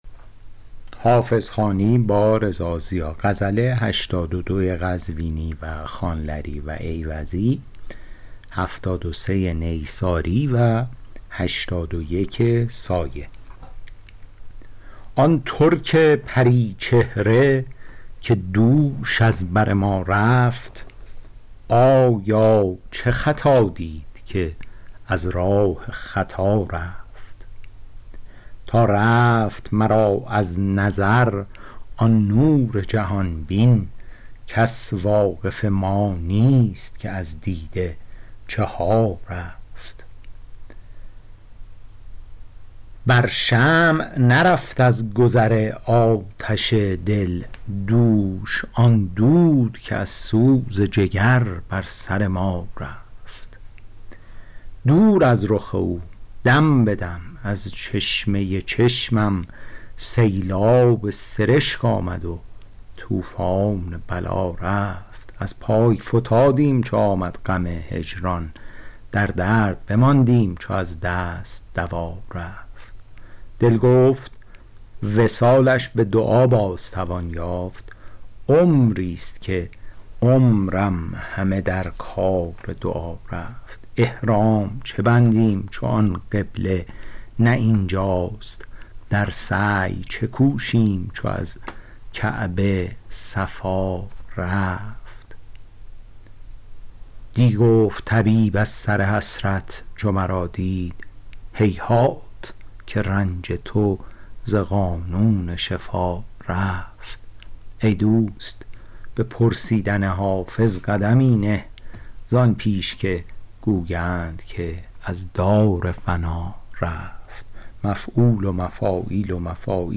شرح صوتی غزل شمارهٔ ۸۲